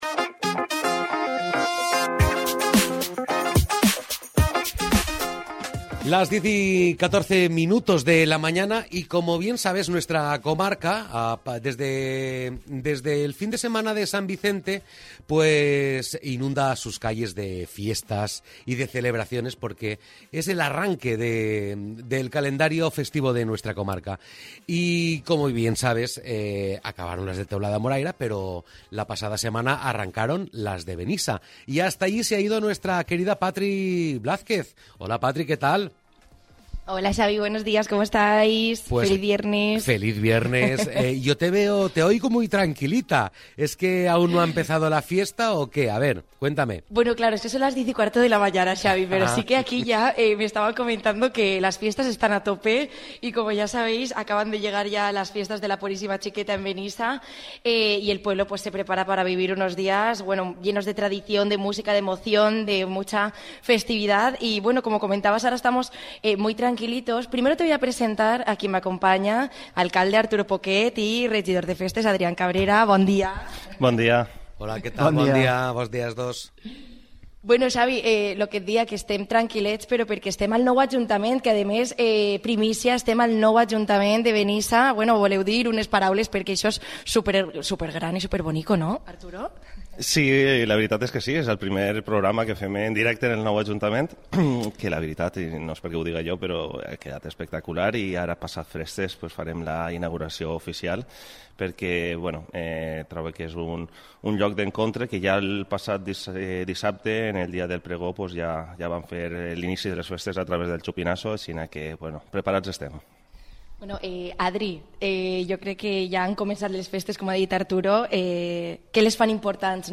El alcalde de Benissa, Arturo Poquet, junto al concejal de Fiestas, Adrián Cabrera, destacan algunas citas para los próximos días
Las Fiestas de la Puríssima Xiqueta tienen por delante uno de los momentos más destacados de la programación. Y en Litoral FM (en conexión con Dénia FM), hemos querido aprovechar la oportunidad para charlar con el alcalde de Benissa, Arturo Poquet, junto al concejal de Fiestas, Adrián Cabrera.
ENTREVISTA-PURISSIMA-XIQUETA-.mp3